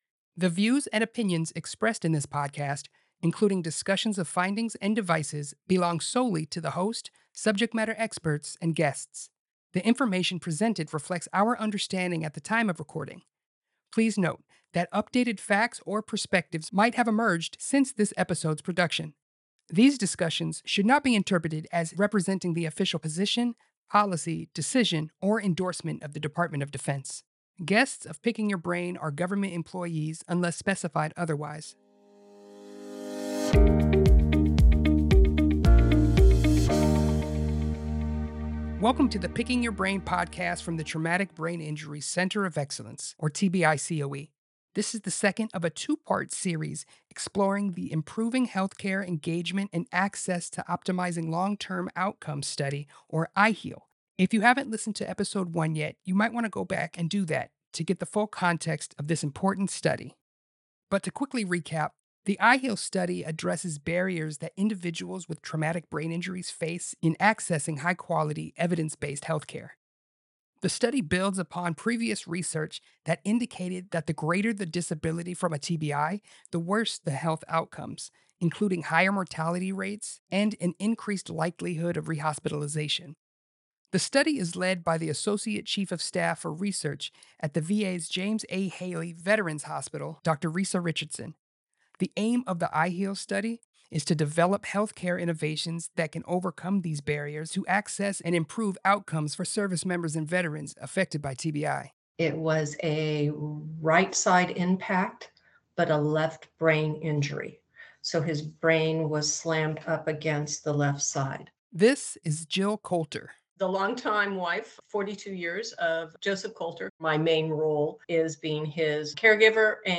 This episode features interviews from TBI subject matter experts who are on the front lines to better understand the issues that contribute to TBI.